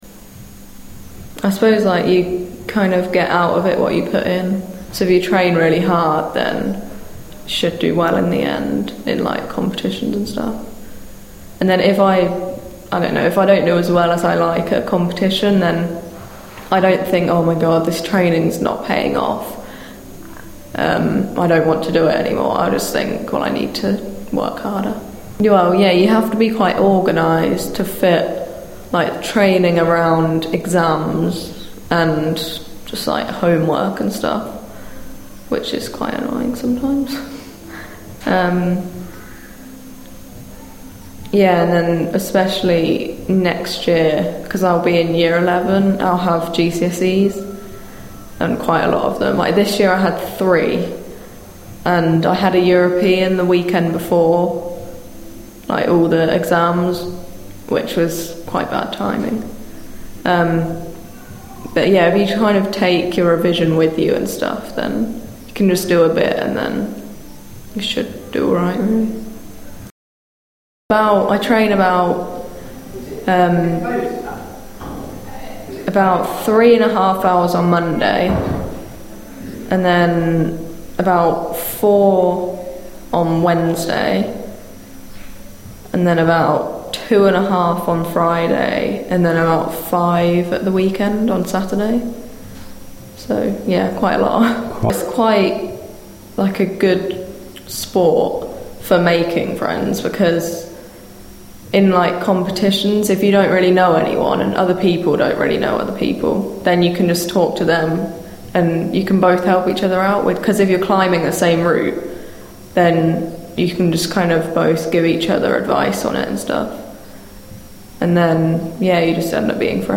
Sjanger: Rhythmic Soul